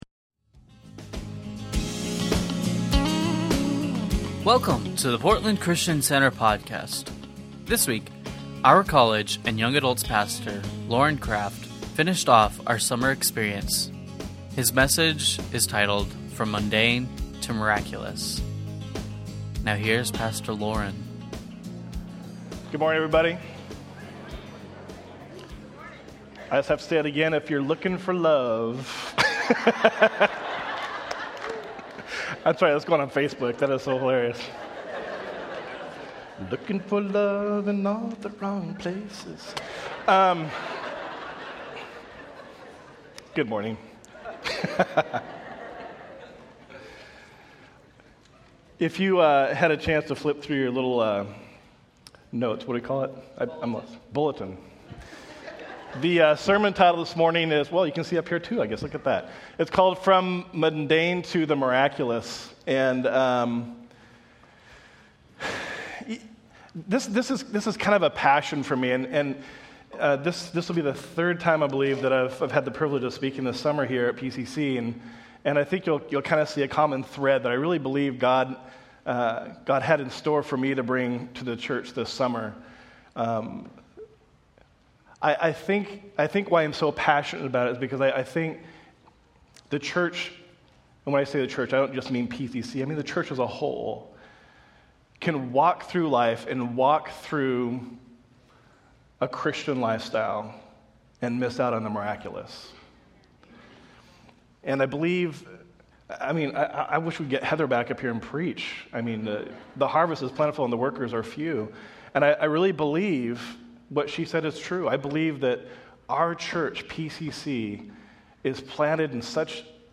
Sunday Messages from Portland Christian Center From Mundane To Miraculous Aug 30 2015 | 00:44:31 1x 00:00 / 00:44:31 Subscribe Share Spotify RSS Feed Share Link Embed